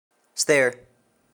Play, download and share placing stair original sound button!!!!
stair.mp3